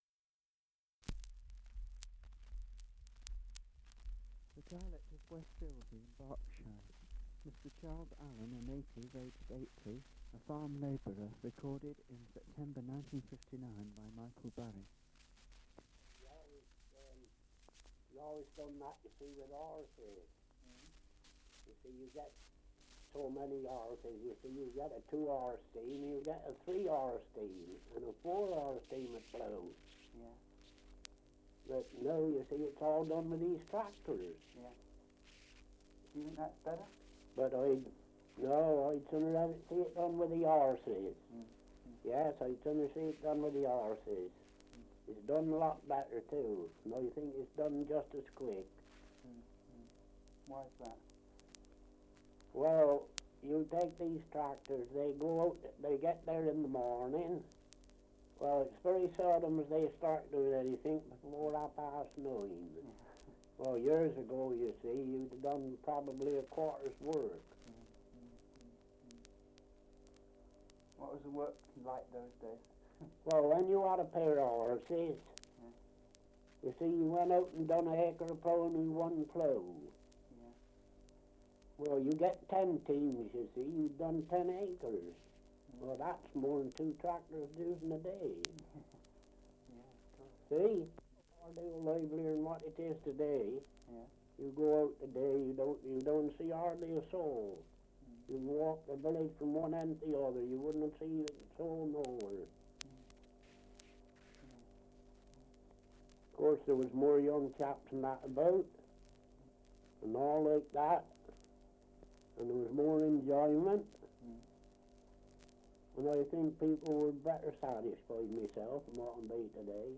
Survey of English Dialects recording in West Ilsley, Berkshire
78 r.p.m., cellulose nitrate on aluminium